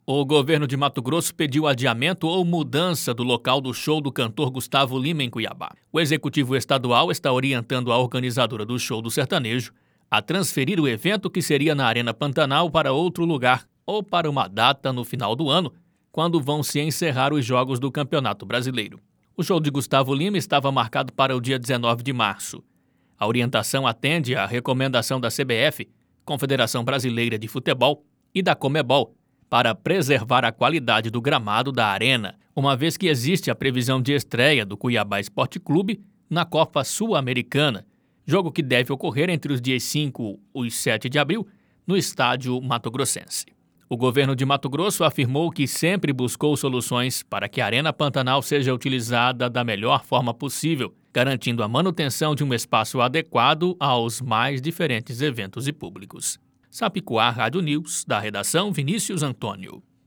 Boletins de MT 19 fev, 2022